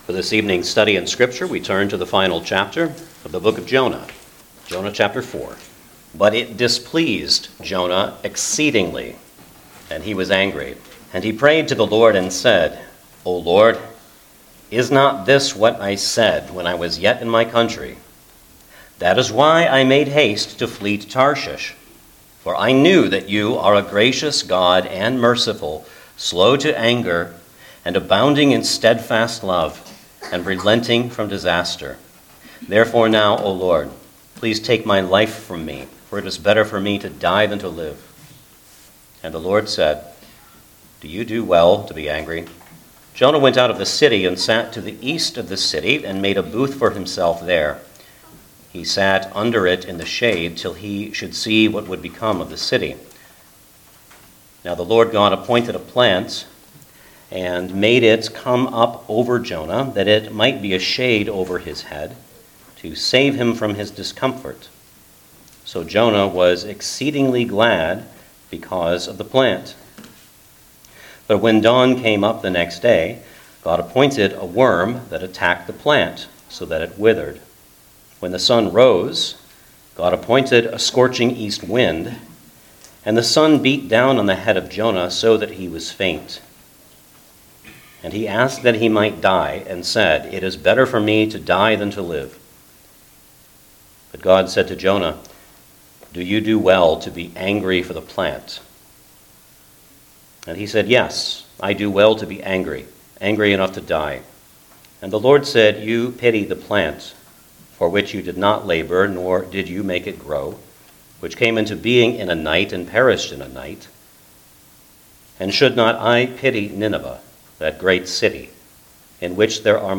Jonah 4 Service Type: Sunday Evening Service Download the order of worship here .